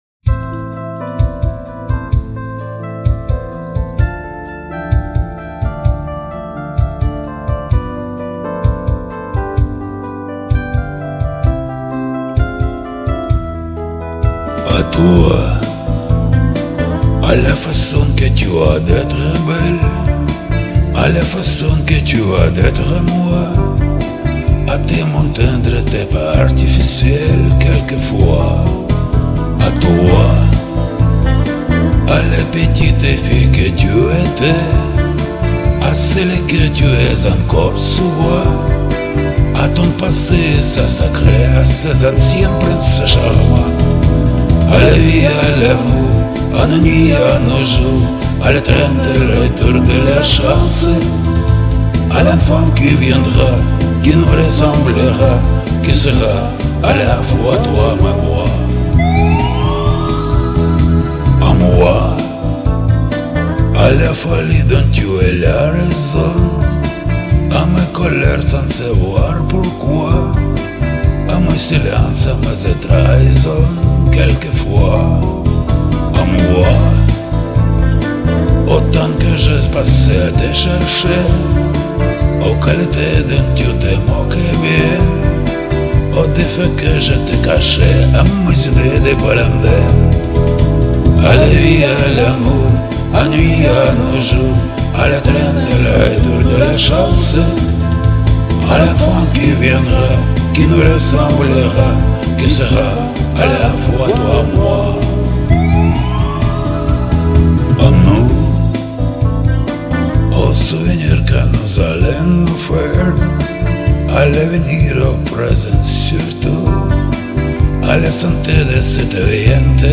Тоже спета давно и с полным набором косяков ))